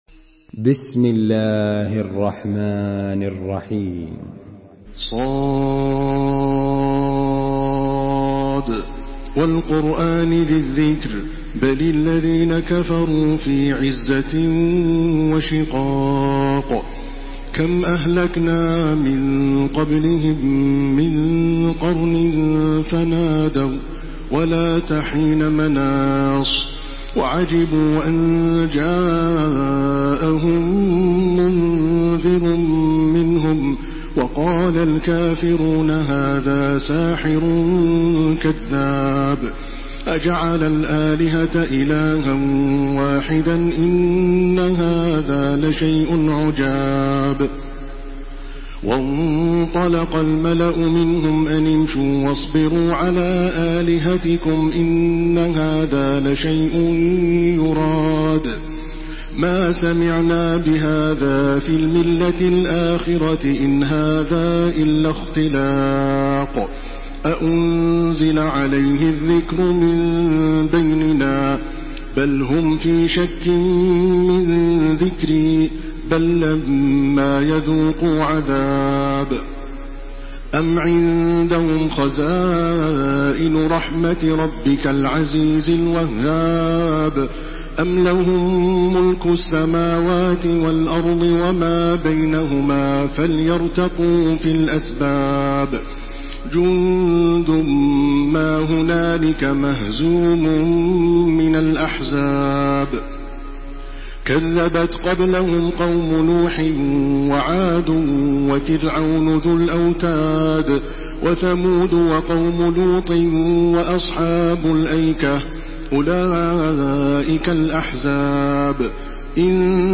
سورة ص MP3 بصوت تراويح الحرم المكي 1429 برواية حفص
مرتل